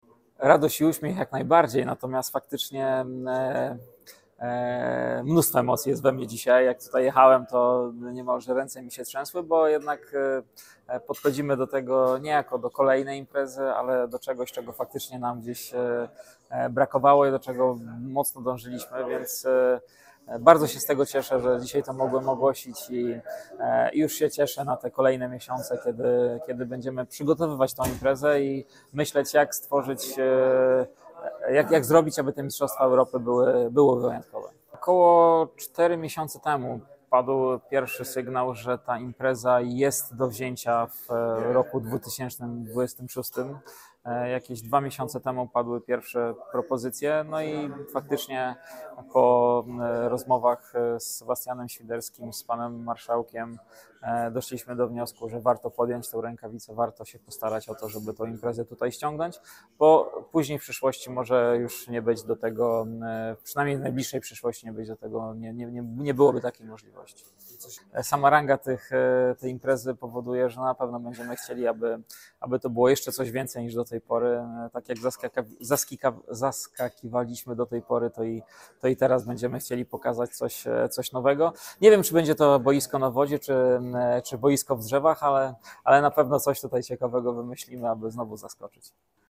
mówił podczas poniedziałkowej konferencji prasowej